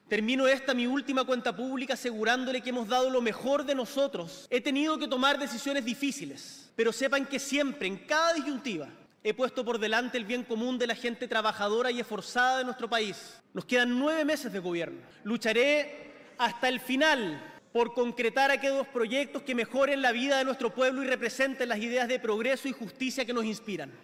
cuna-tl-discurso-boric-gobierno.mp3